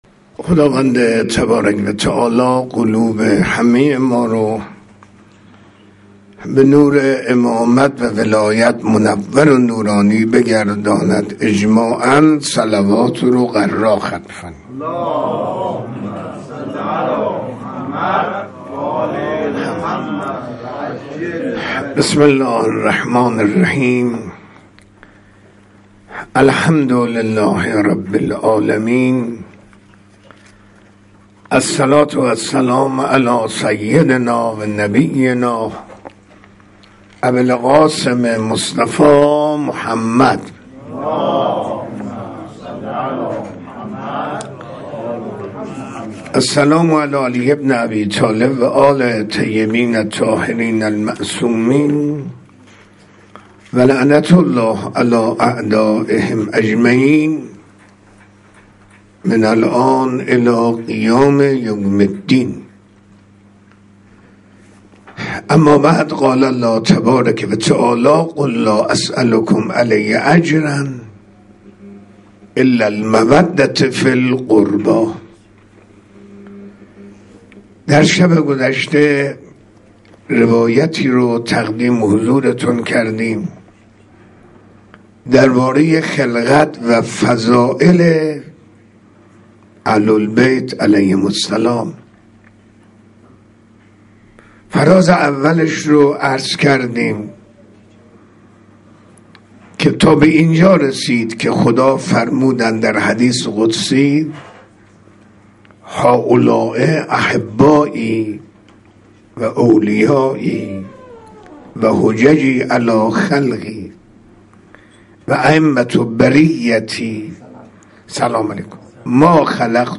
منبر